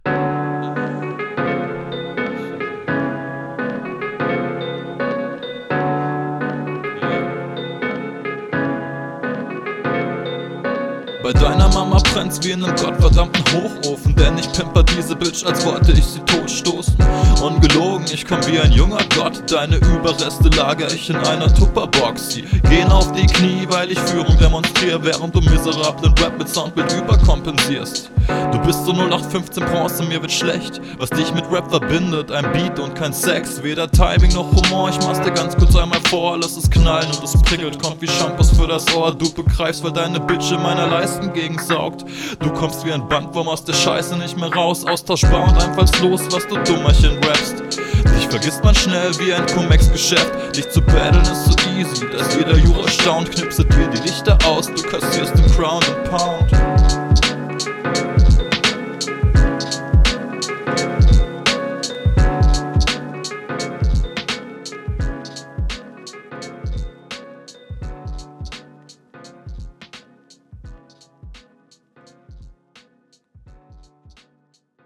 attitude kommt nochmal geiler rüber flow auch seeehr entspannt und stimme passt auch super aufm …